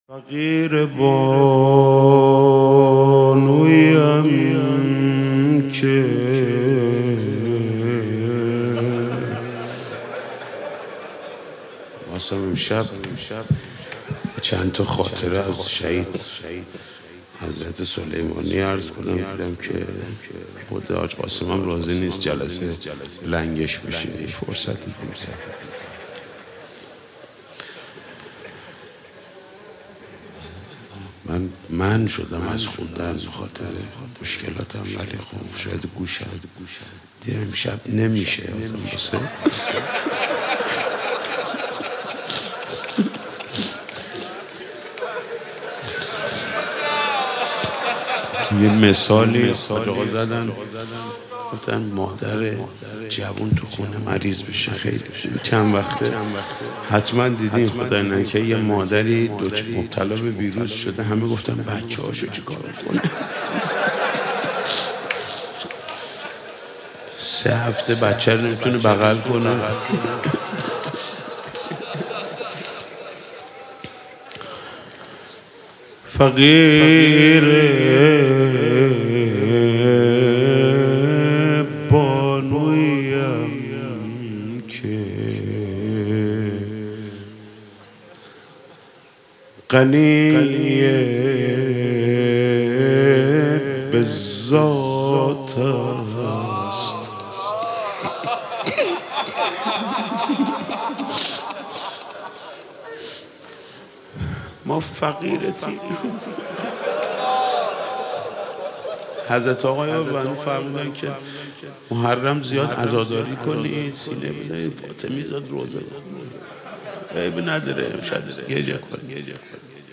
مداحی محمود کریمی در فاطمیه 1399 - تسنیم
محمود کریمی با حضور در هیأت عبدالله بن الحسن(ع) به مداحی و روضه‌خوانی برای حضرت زهرا (س) پرداخت.
به گزارش خبرنگار فرهنگی خبرگزاری تسنیم، محمود کریمی مداح اهل بیت(ع) یکشنبه هفتم دی‌ماه با حضور در هیأت عبدالله بن الحسن(ع) به روضه‌خوانی و مداحی پرداخت.
در ادامه صوت عزاداری محمود کریمی را می‌شنوید: